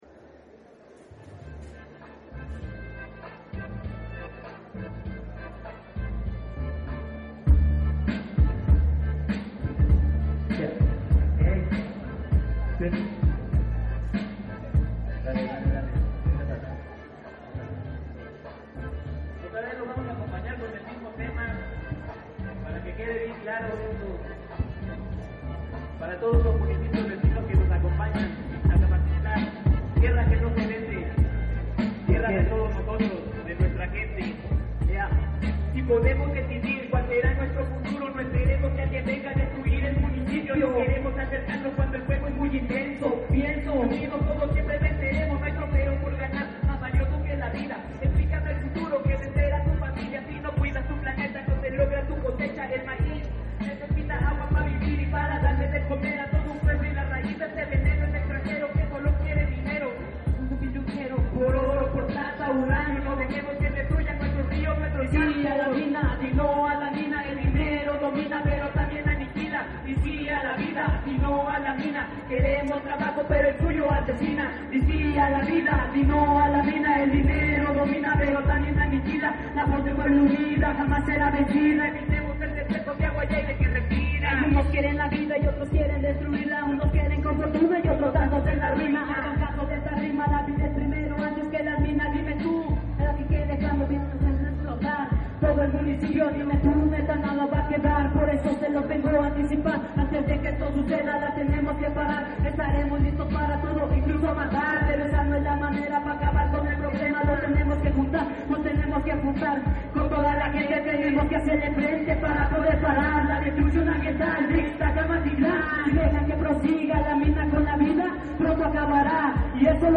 Este 22 de junio se llevó a cabo el foro Proyectos de muerte en la sierra norte de Puebla y su impacto en los Derechos Humanos, en la cancha municipal de Ixtacamaxtitlán en la Sierra Norte de Puebla (SNP), al cual acudieron más de mil personas, habitantes y autoridades de más de 20 comunidades de esa zona.
Hip_hop_no_a_las_mineras.mp3